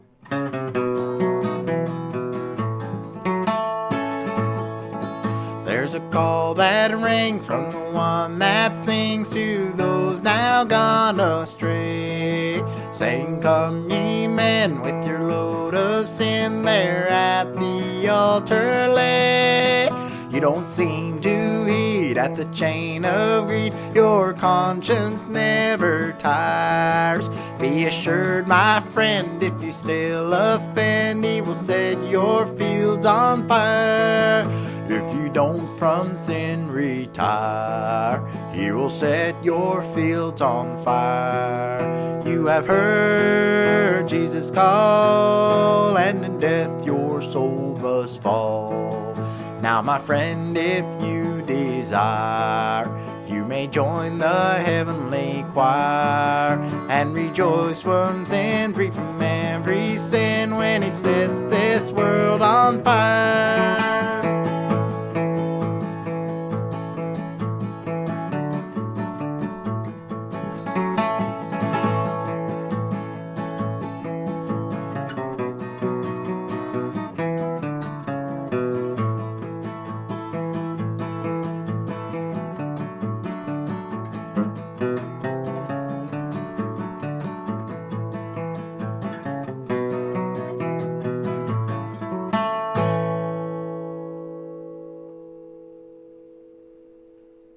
key of A